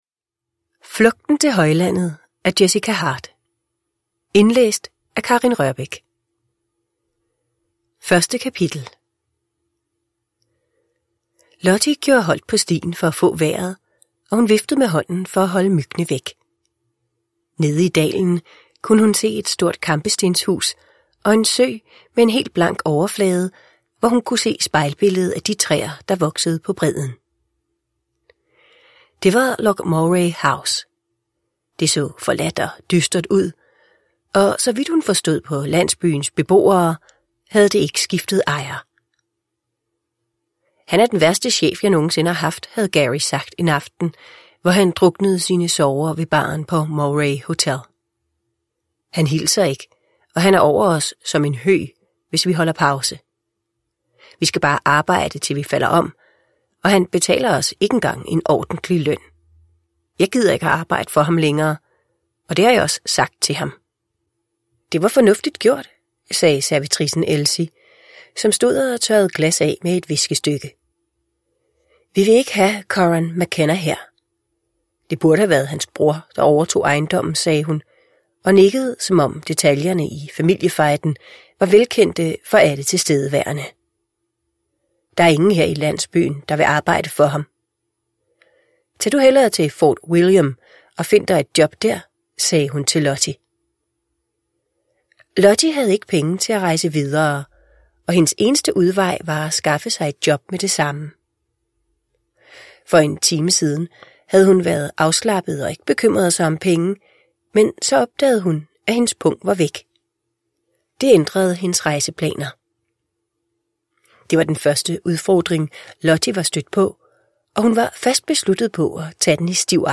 Flugten til højlandet – Ljudbok – Laddas ner